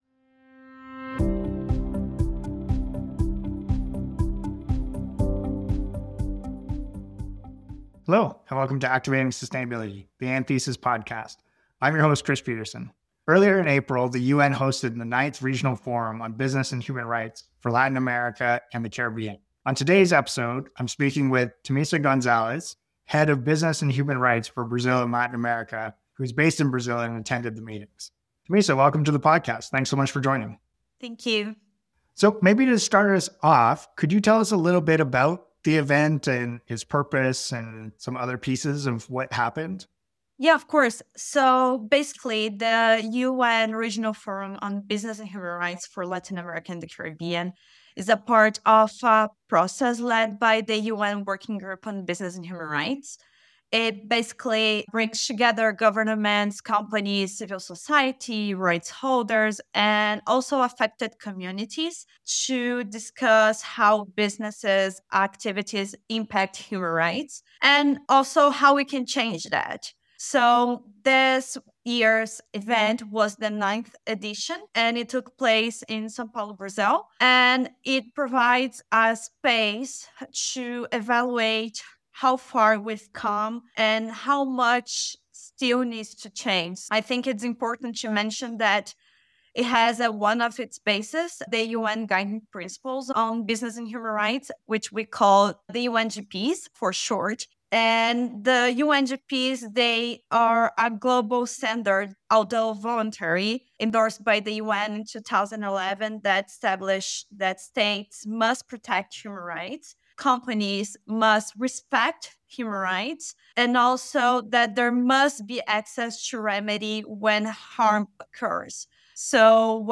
Why should retailers embrace the circular economy? | Conversations from Climate Week New York 25:44 Play Pause 7d ago 25:44 Play Pause Play later Play later Lists Like Liked 25:44 Recorded live at Climate Week New York, in this episode we ask ‘why should retailers embrace the circular economy?’